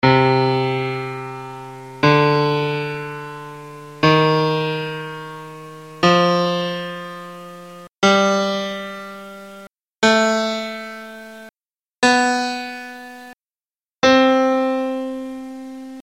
Melodic Minor Chord Scale
The chords are displayed as played on the 2nd string set (middle 4 strings).
This shows the C minor melodic scale (minor major 7 scale)
minor_scale.mp3